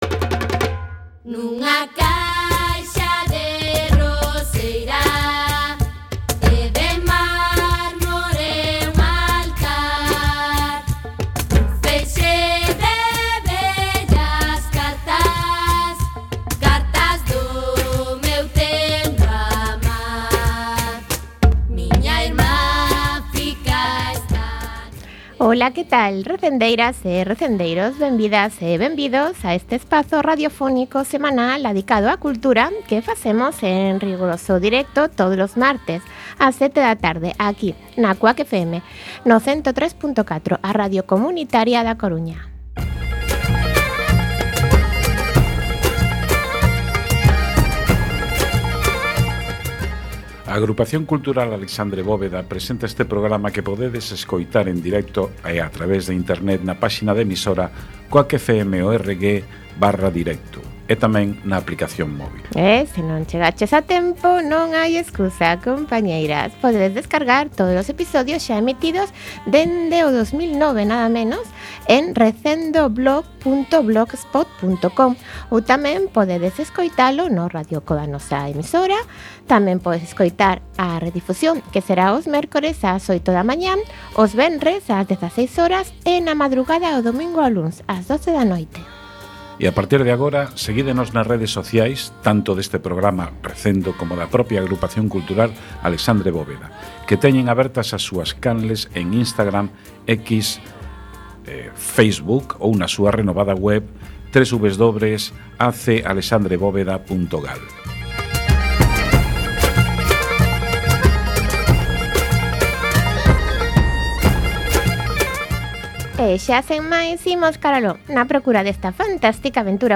17x1 Entrevista